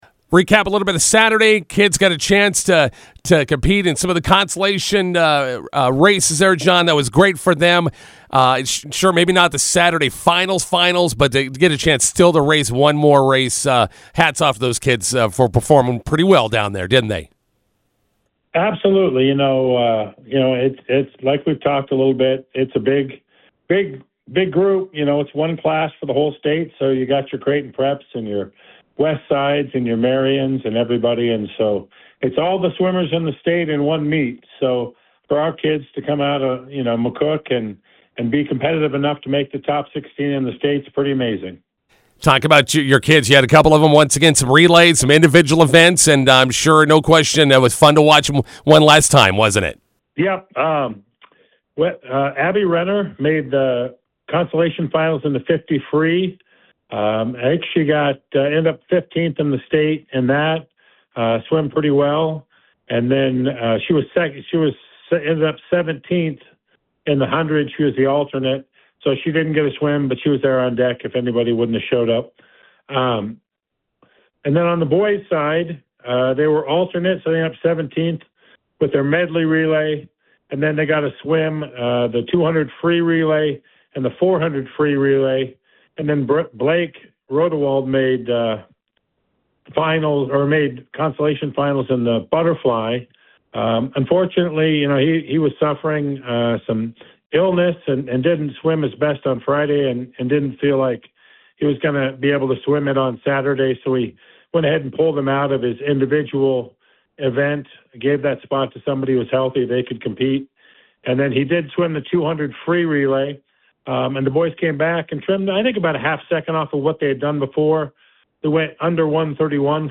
INTERVIEW: Bison swimmers wrap up season with consolation races on Saturday.